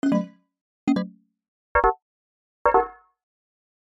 Error (Disaparaging Chimes)